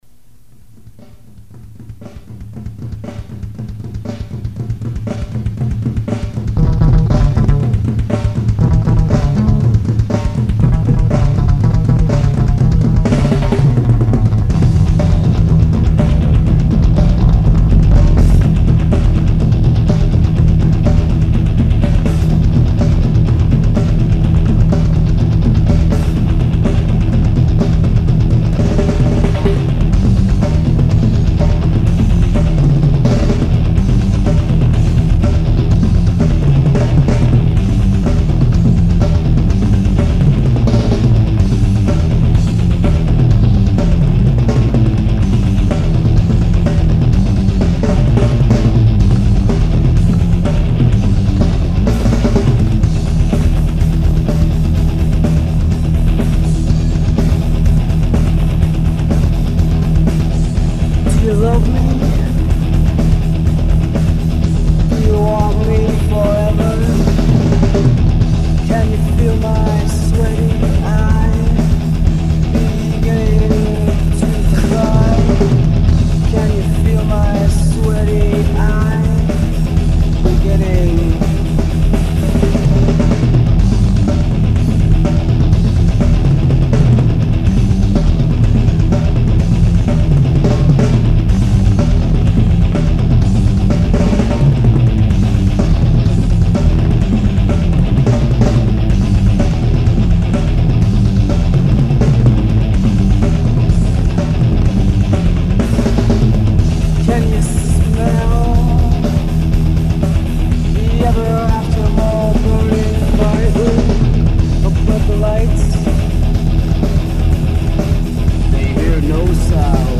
Guitar and Vocals
Bass
Drums